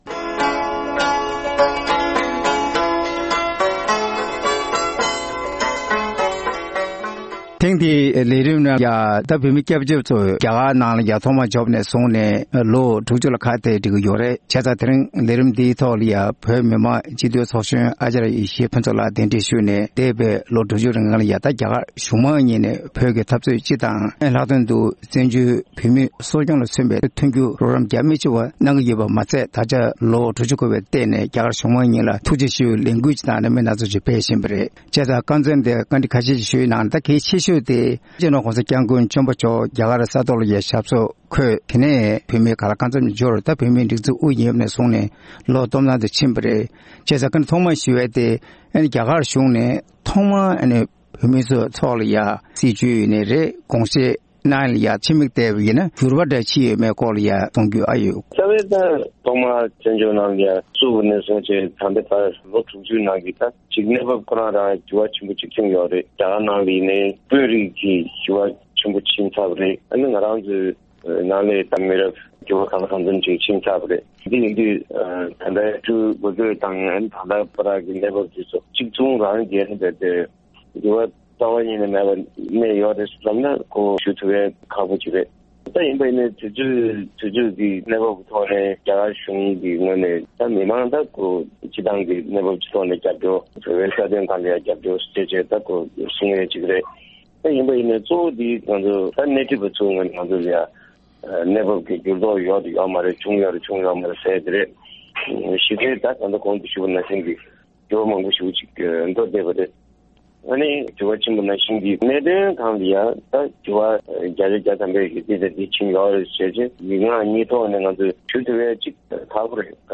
གླེང་མོལ་གནང་བ་ཞིག་གསར་རོགས་ཞུ༎